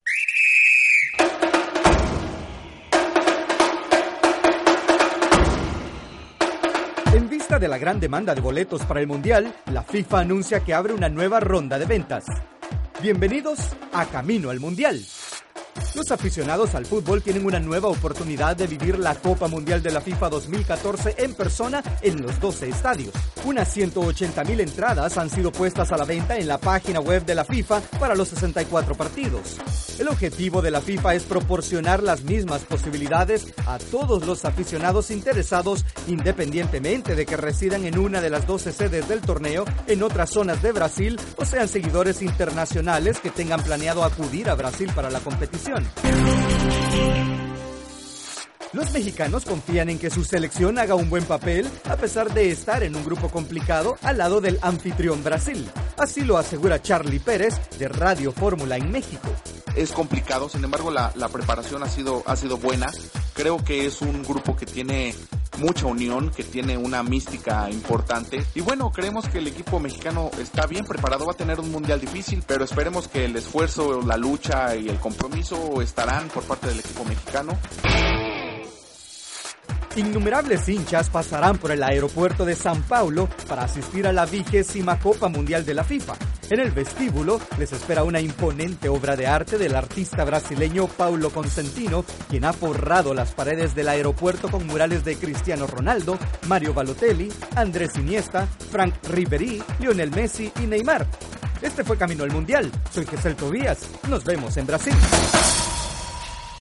El informe actualizado de Camino al Mundial de Fútbol Brasil 2014.